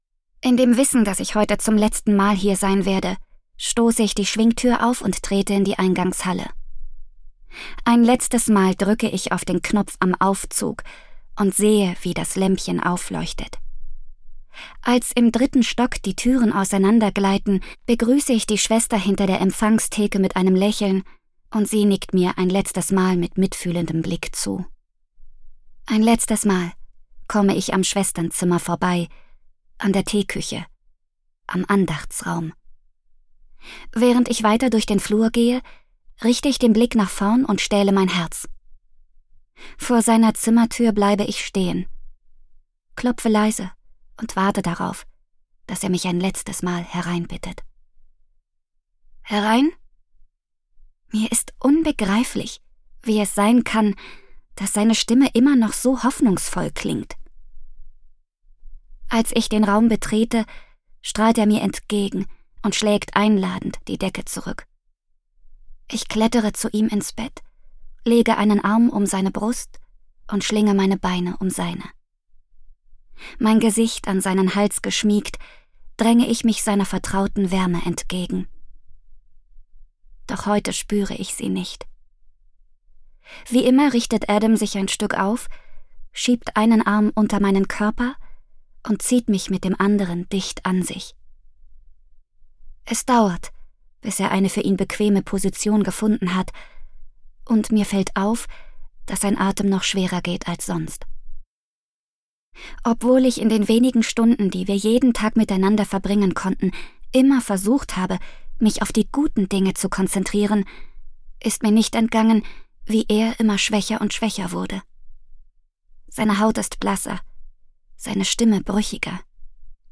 Love and Confess (DE) audiokniha
Ukázka z knihy